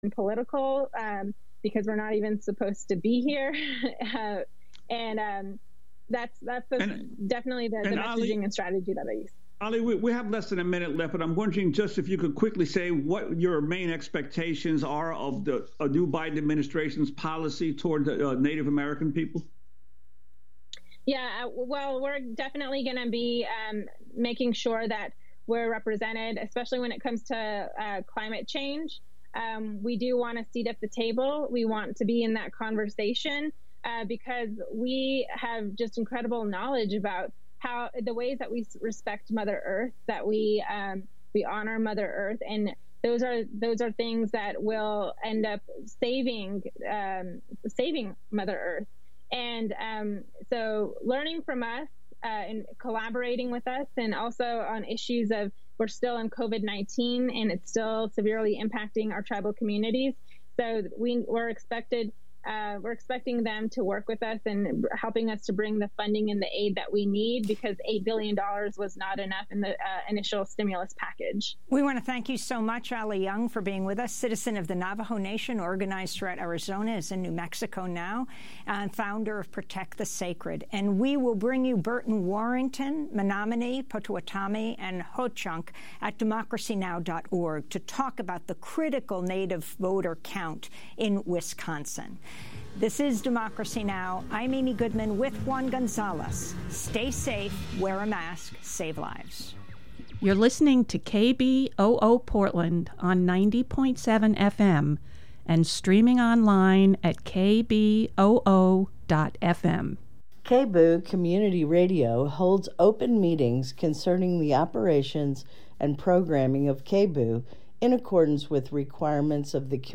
Non-corporate, volunteer-powered, local, national, and international news